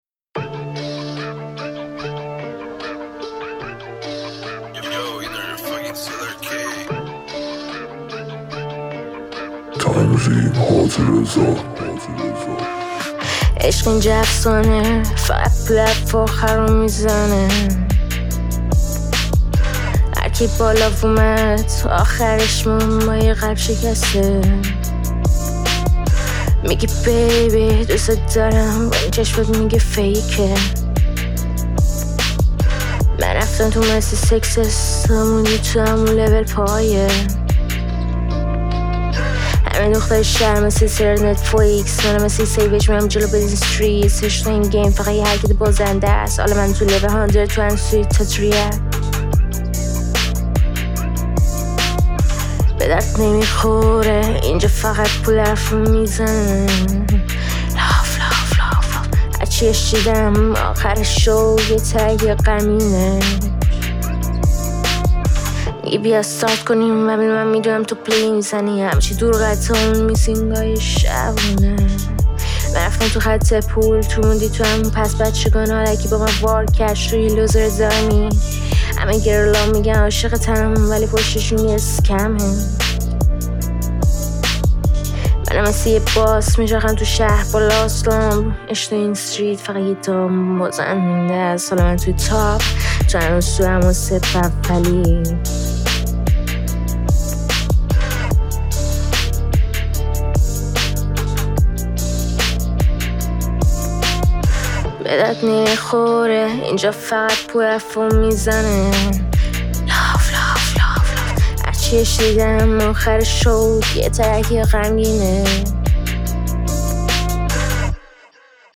تهیه شده در(استدیو رکورد)